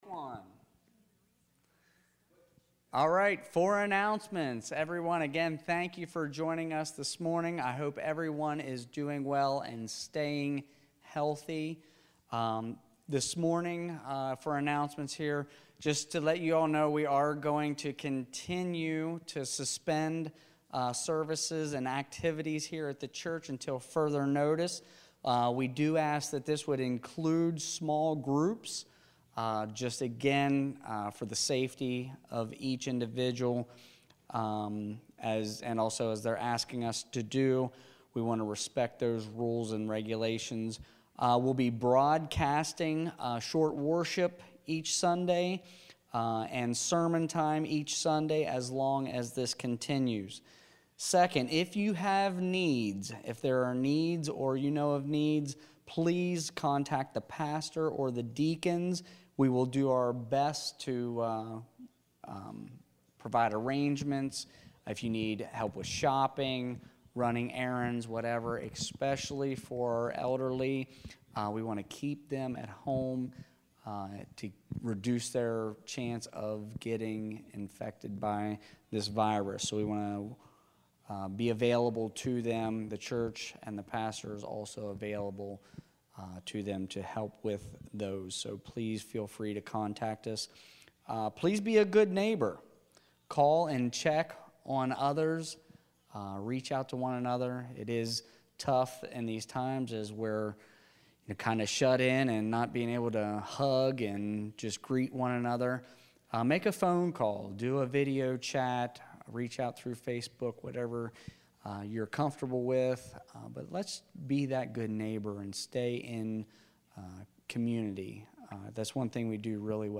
Announcements/Opening Prayer/ Sermon: “God Is My Rock” Psalm 18
announcements.mp3